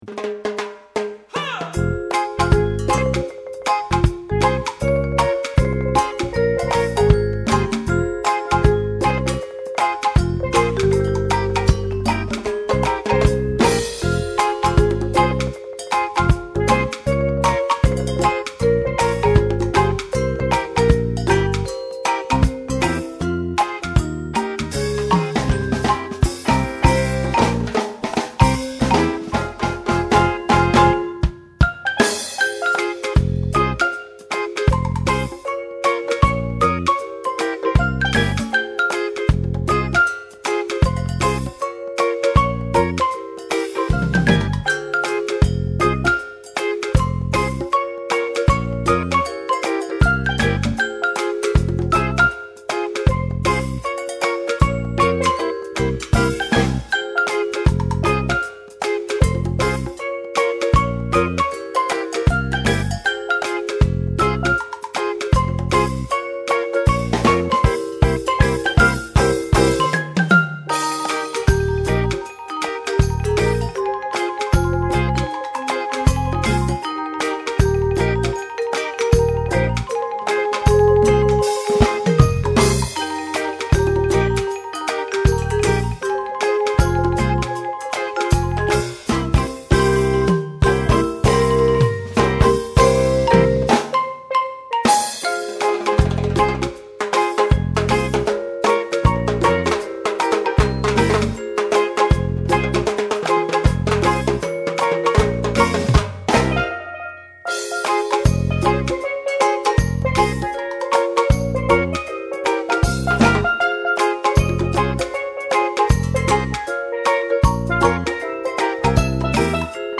Medium Percussion Ensemble w/Rhythm Section